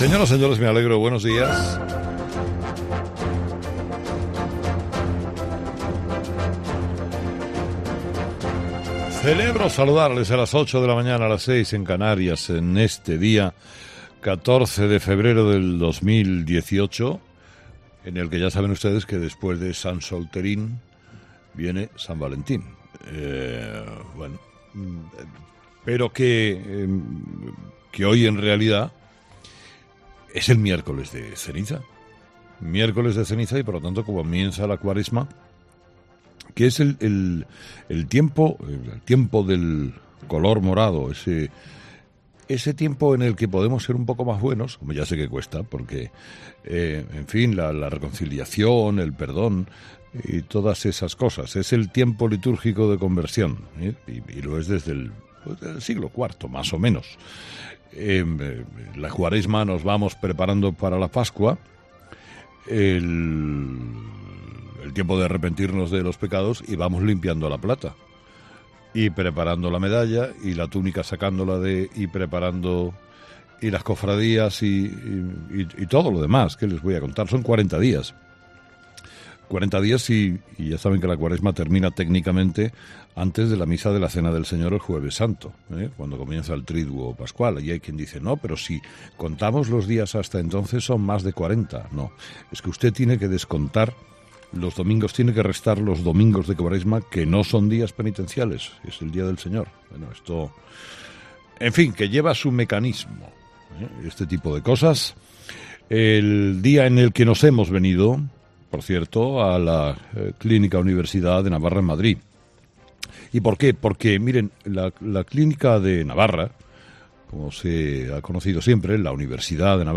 Monólogo de las 8 de Herrera 'Herrera en COPE'